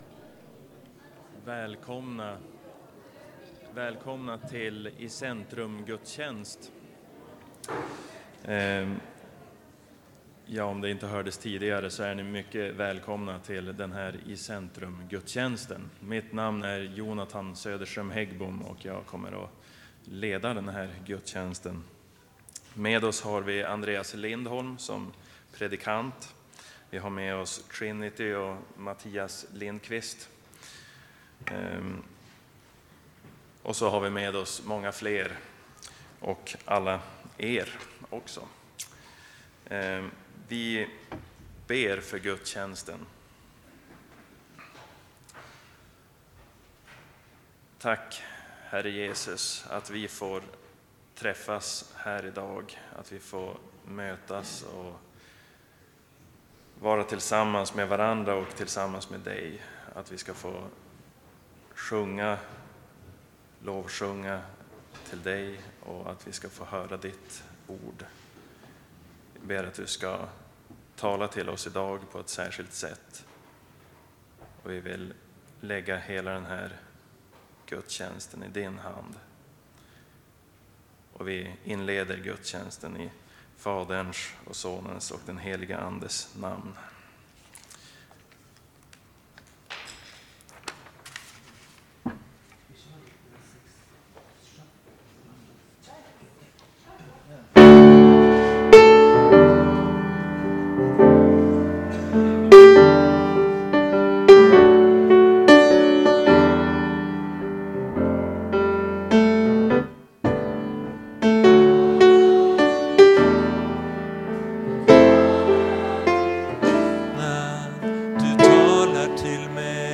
Dans Crew dansade innan predikan.
Kören Trinity sjöng till gudstjänsten.